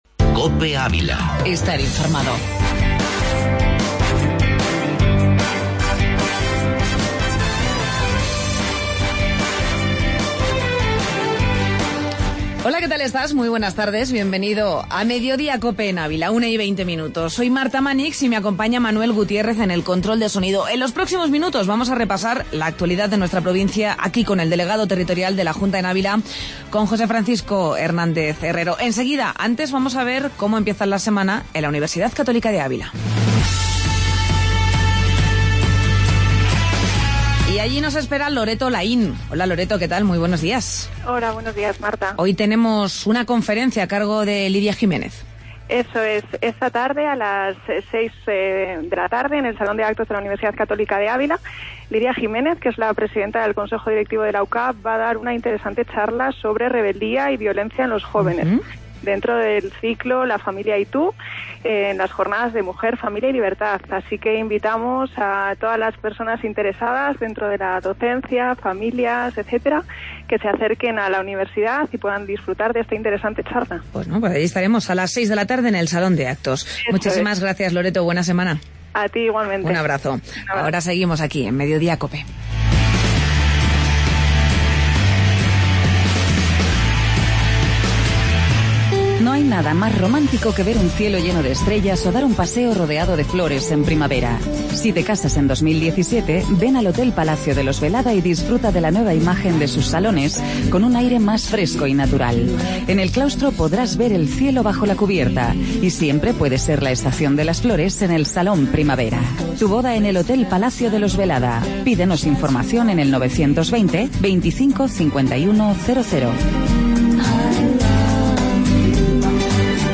AUDIO: Entevista Delegado de la Junta en Ávila